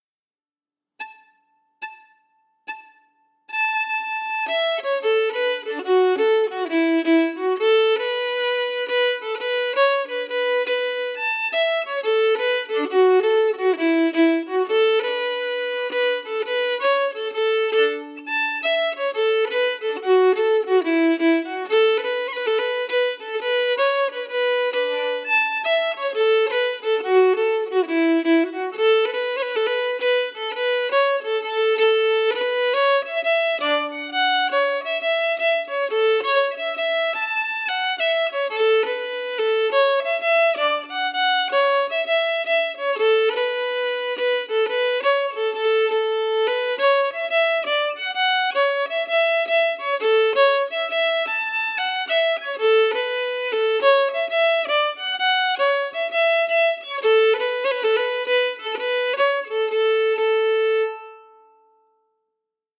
Key: A
Form: Jig
Slow for learning